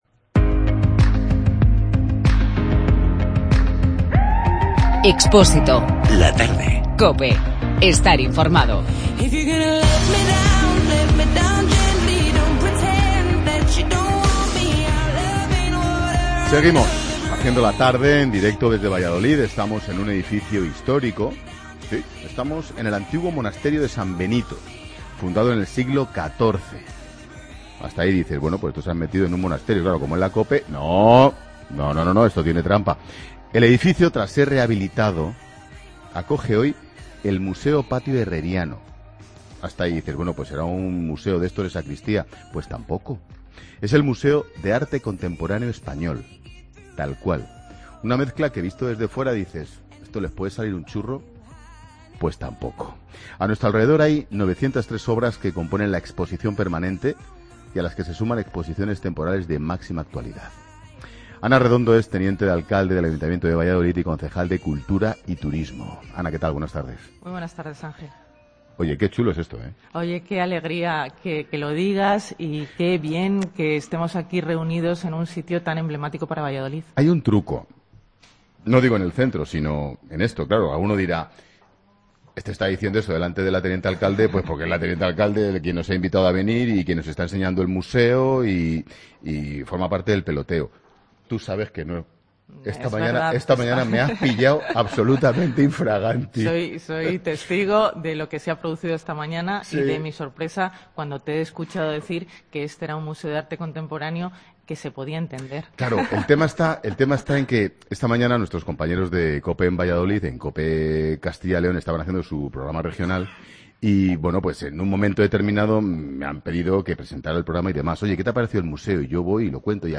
Ana Redondo, teniente de alcalde del Ayuntamiento de Valladolid y concejala de Cultura y Turismo, asegura que en el Museo Patio Herreriano, desde donde se ha realizado 'La Tarde' de Expósito, se pueden ver todas las vanguardias de arte moderno que generó la pintura del 1916 al 1956.